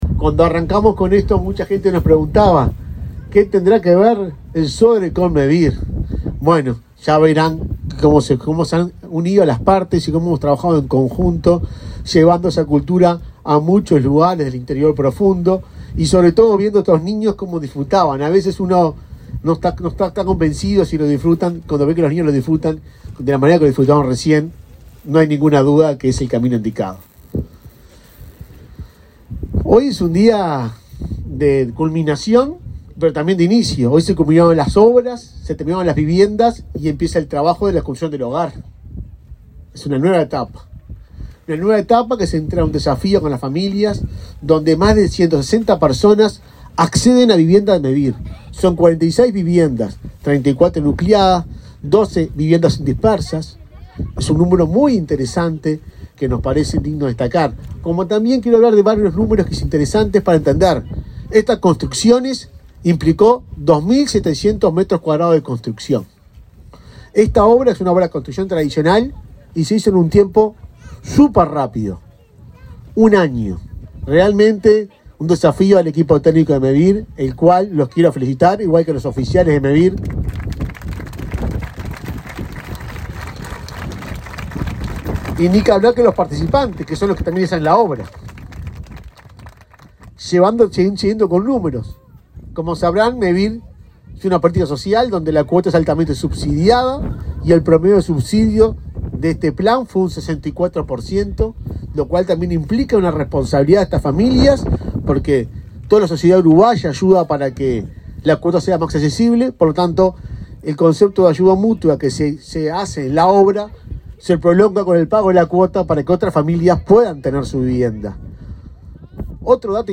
Palabras de autoridades en inauguración de viviendas de Mevir
El presidente del organismo, Juan Pablo Delgado, y el subsecretario de Vivienda, Tabaré Hackenbruch, señalaron la importancia de este programa.